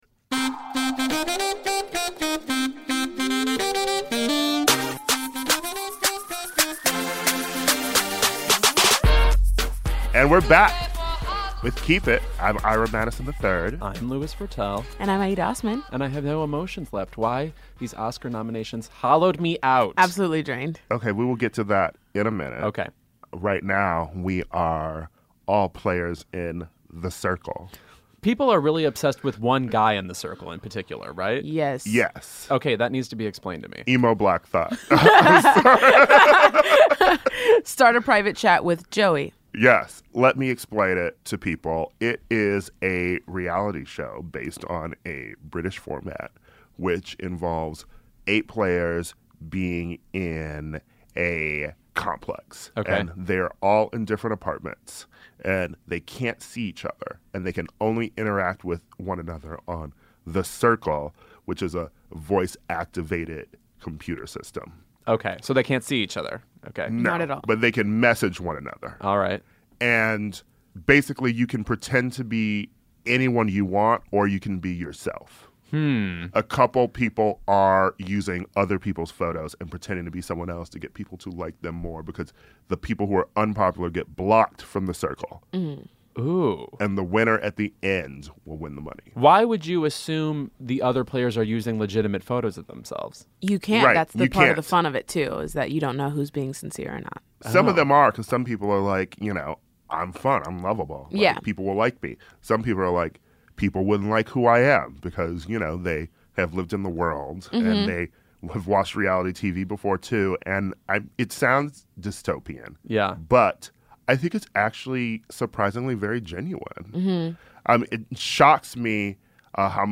Plus, You and Magicians showrunner Sera Gamble joins to discuss why we're all obsessed with Netflix's dark, twisted stalker drama.